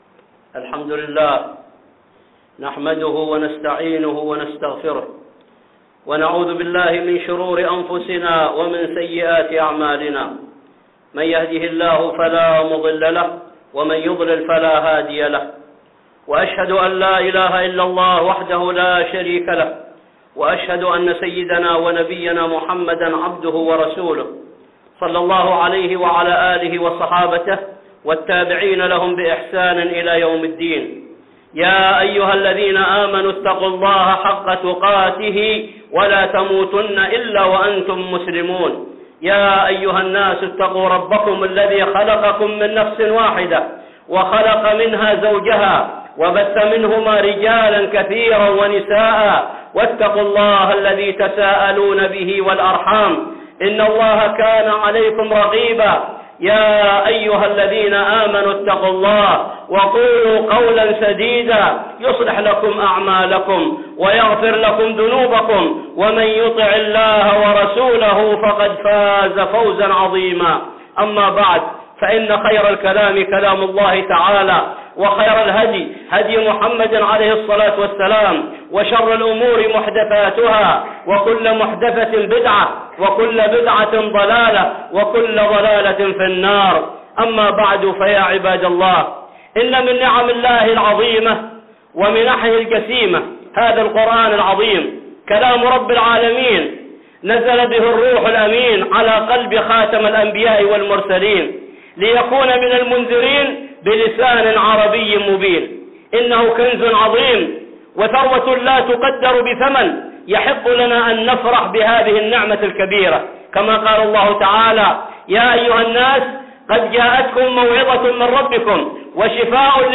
(خطبة جمعة) تدبر القرآن
خطبة جمعة بعنوان تدبر القرآن